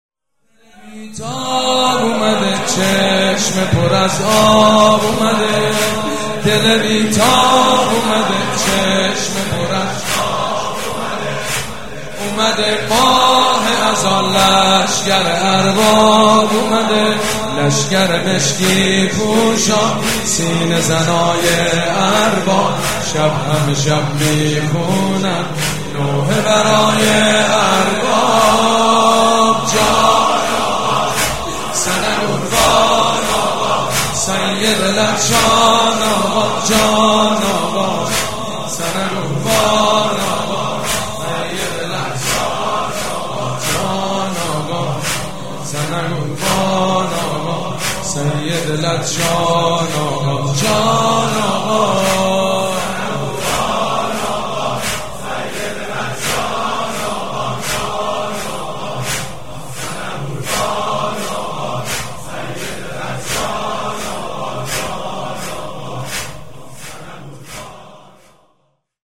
مداح
مراسم عزاداری شب عاشورا